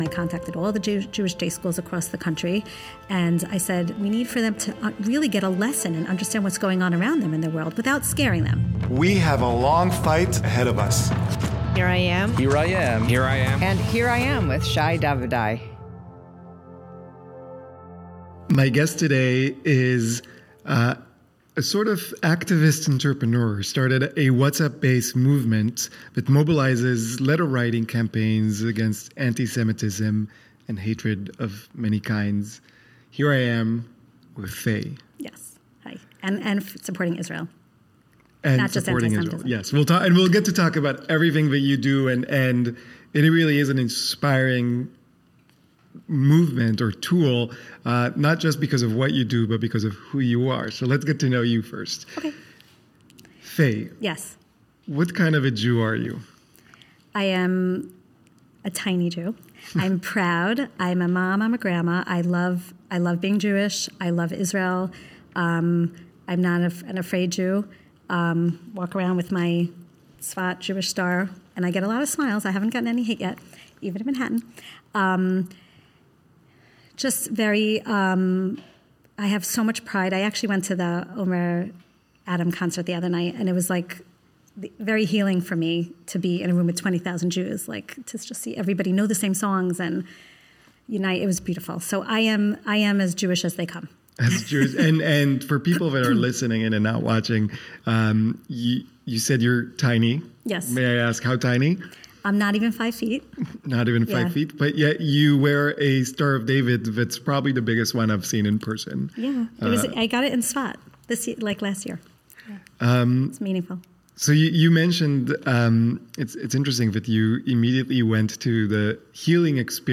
Tune in for an inspiring conversation about resilience, community, and making a difference.